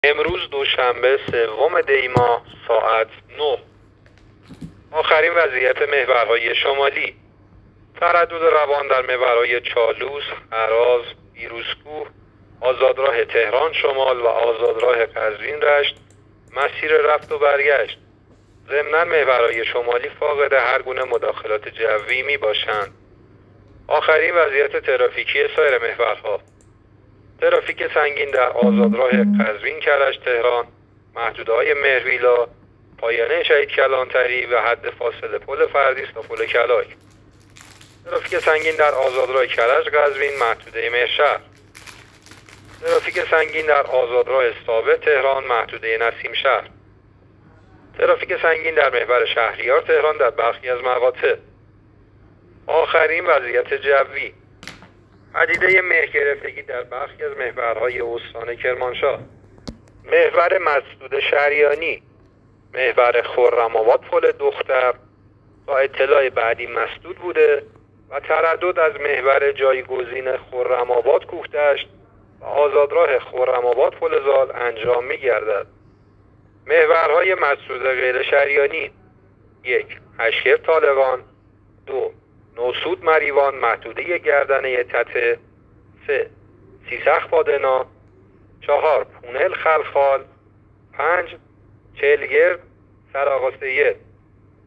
گزارش رادیو اینترنتی از آخرین وضعیت ترافیکی جاده‌ها تا ساعت ۹ سوم دی؛